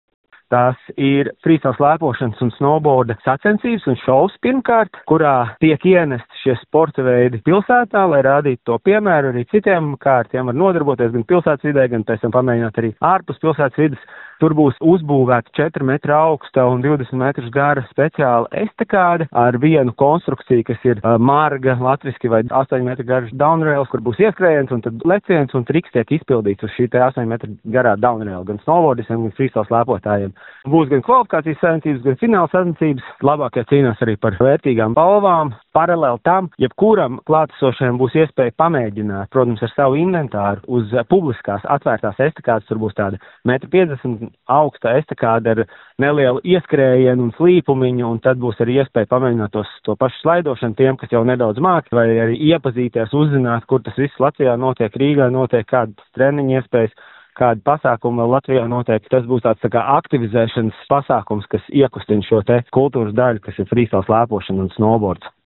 profesionāls kalnu slēpotājs un treneris